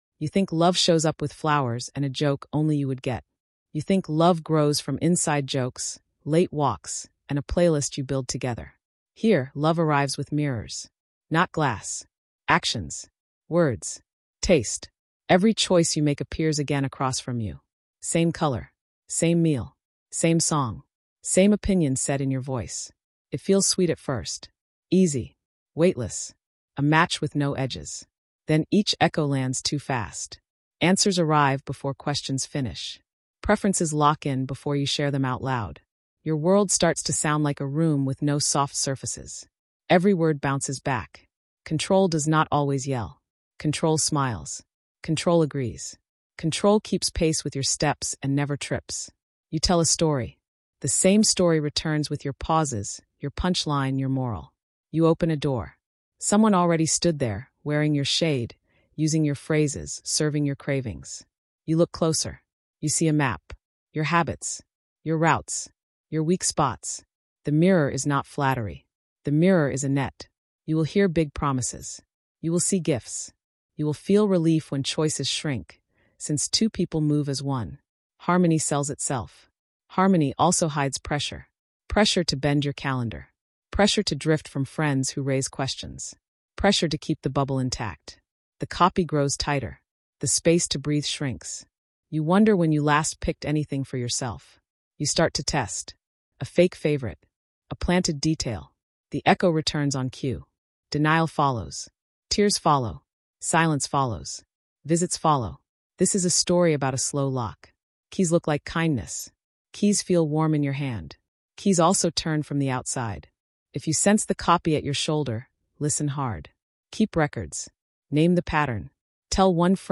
This is a Netflix-style suspense thriller told in plain speech. Episode 1 covers Chapters 1–6, from the first copy to the surge of gifts and future talk.